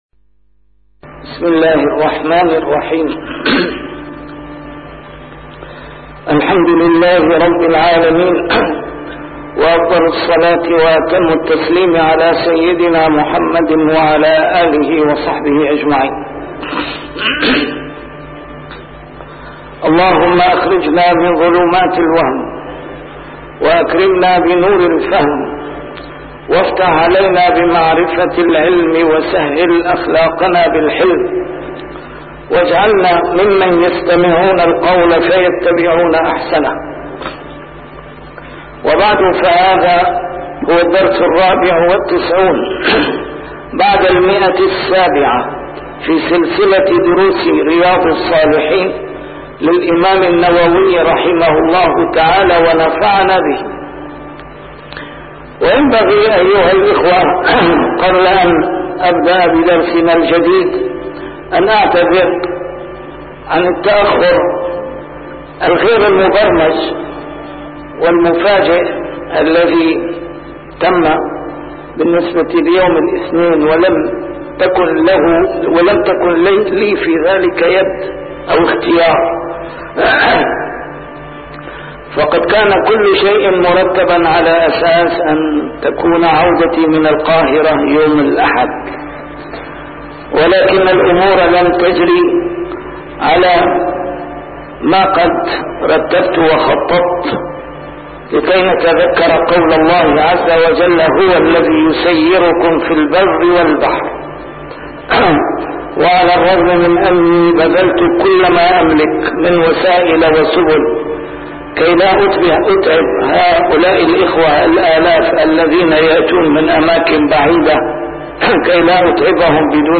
A MARTYR SCHOLAR: IMAM MUHAMMAD SAEED RAMADAN AL-BOUTI - الدروس العلمية - شرح كتاب رياض الصالحين - 794- شرح رياض الصالحين: يوم الجمعة